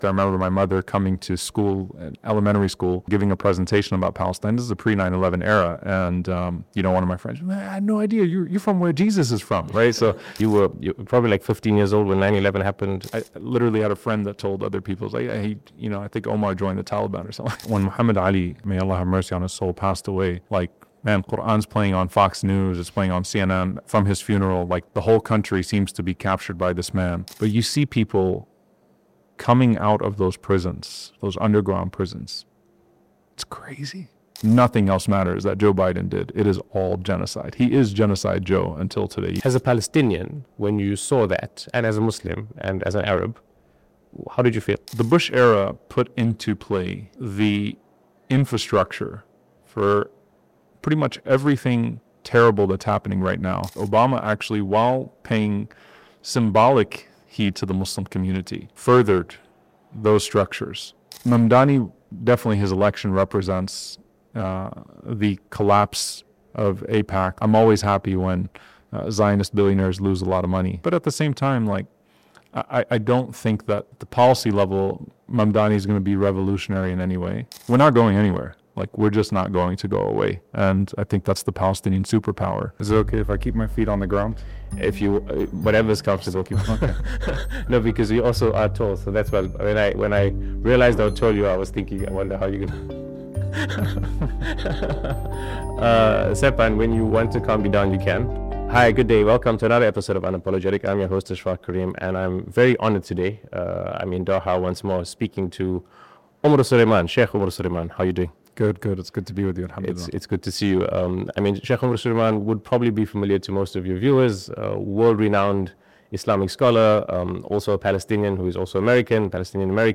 In this conversation with UNAPOLOGETIC, Imam Omar Suleiman reflects on two decades of global politics, the Palestinian struggle, Islamophobia in America, and the meaning of justice in a collapsing world order.